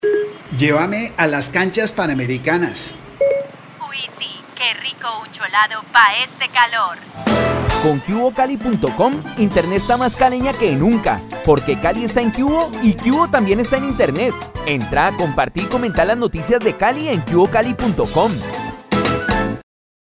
Siri habla con acento caleño
Por eso es que les decimos que ahora 'Siri' habla con acento caleño, porque al estar Q'hubo en internet, una marca tan caleña como vos, teníamos el reto de poner a hablar a 'Siri' a nuestro estilo.